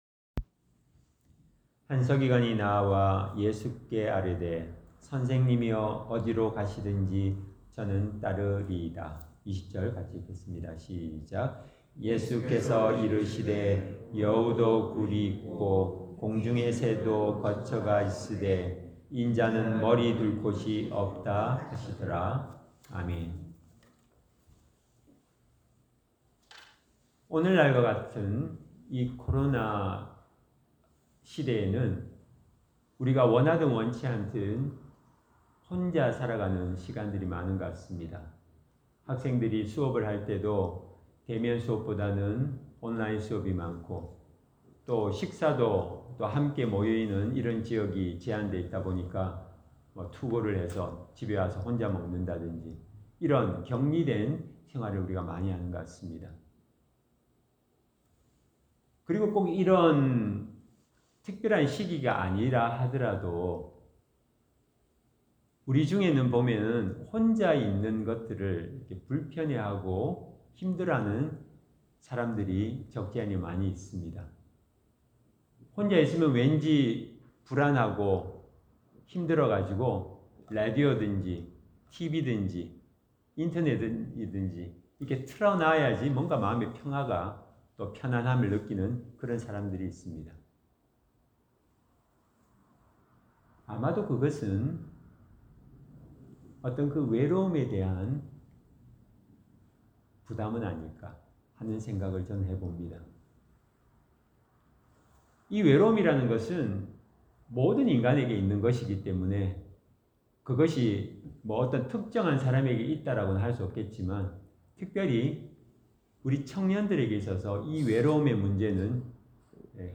Service Type: 주일 예배